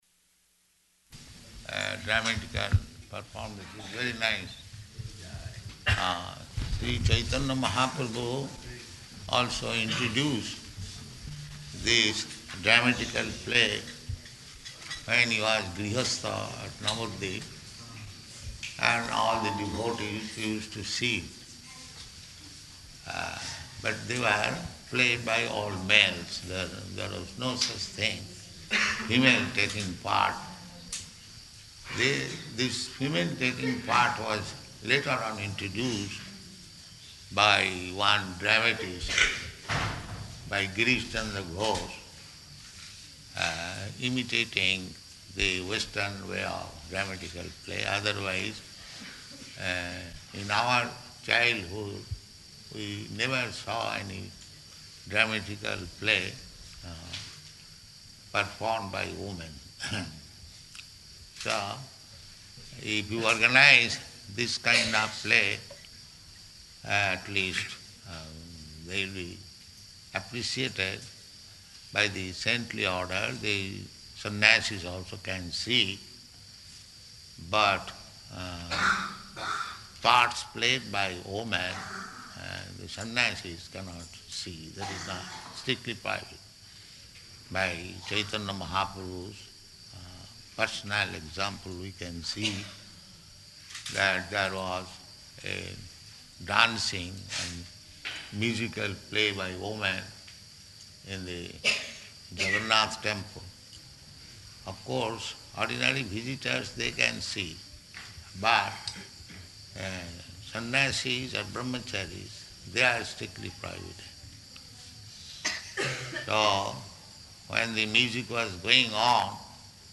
Lecture After Play
Location: Māyāpur